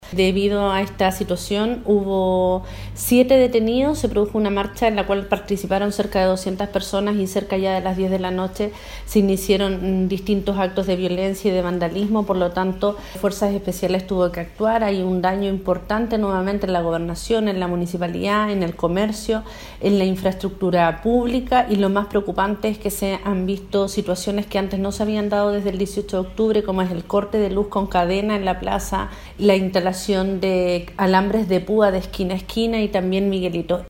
La Gobernadora de Llanquihue, Leticia Oyarce, cifró en 7 los detenidos tras la reciente jornada.